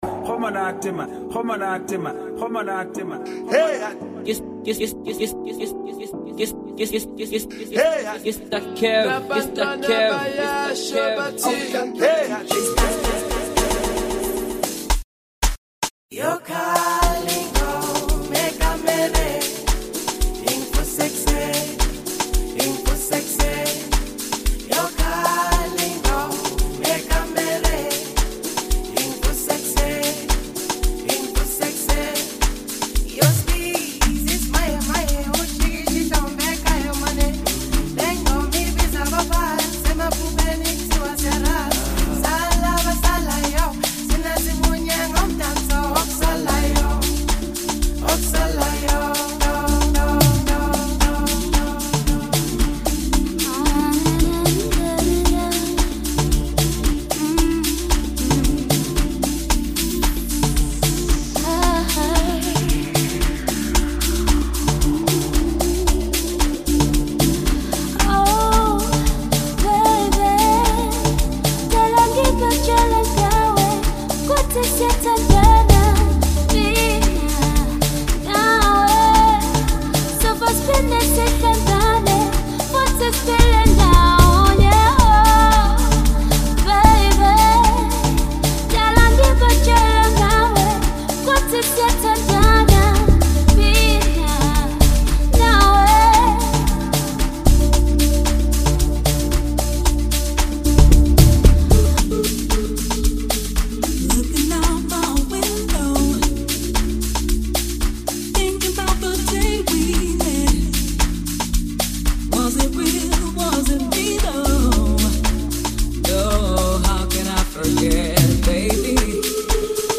Check out this his new 15Mins mixtape.
Amapiano